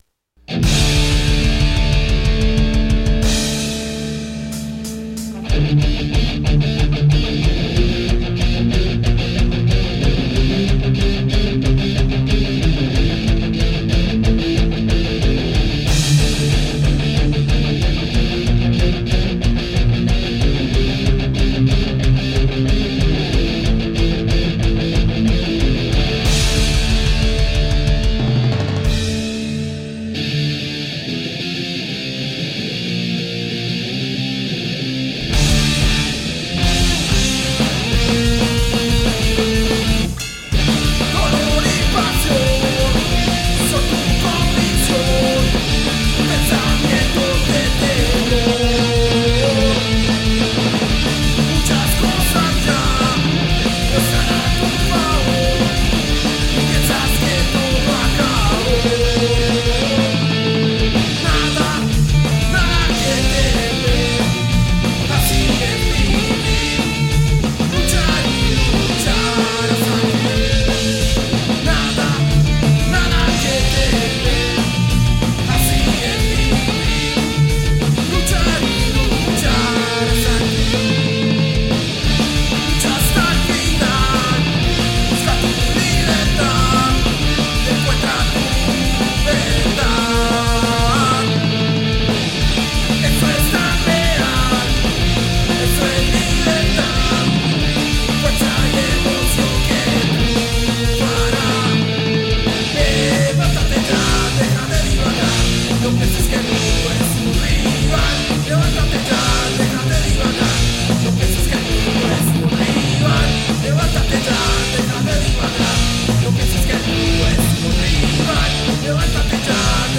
Skate-Punk Hardcore